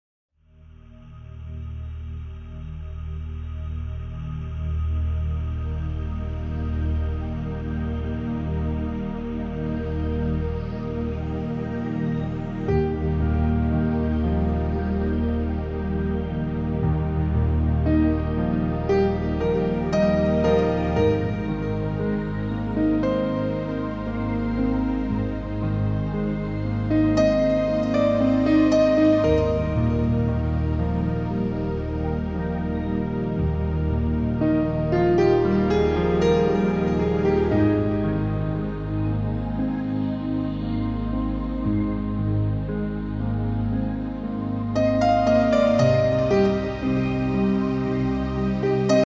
Fantastische Meditatonsmusik mit 16 Klavierstücken